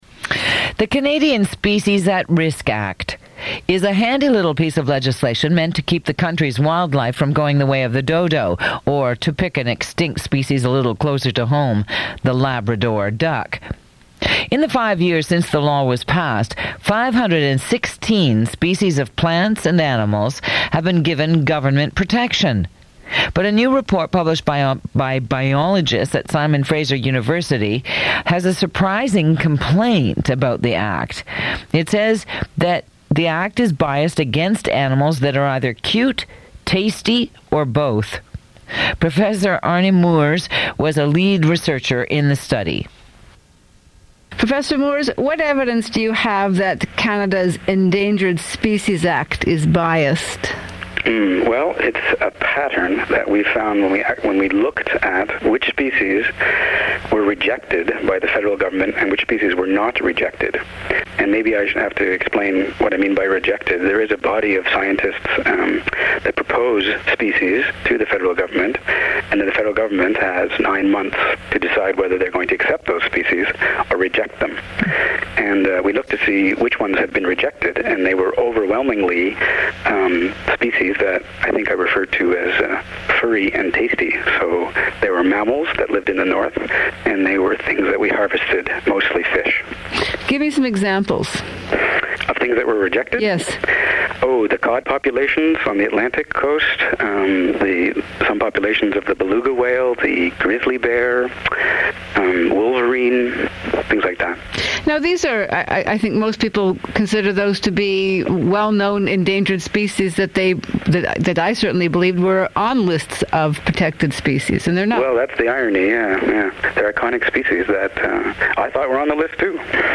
Interview on CBC's As It Happens (9MB .mp3)